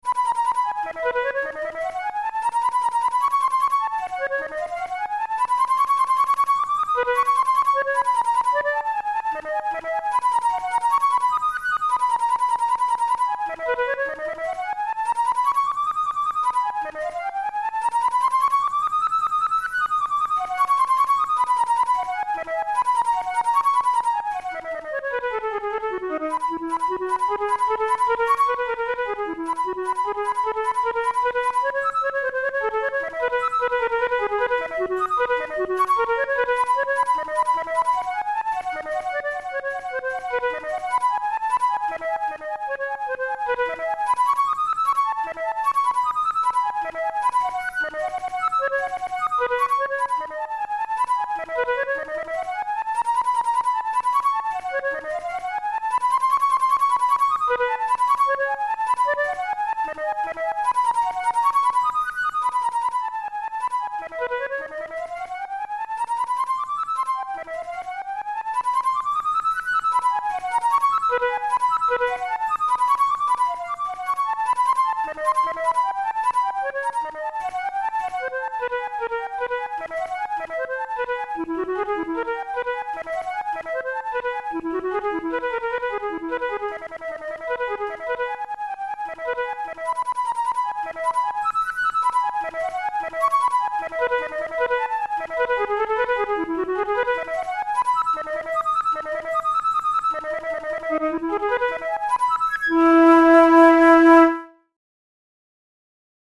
The indication at the beginning, mit doppeltem Zungenstoss, instructs the player to use double tonguing.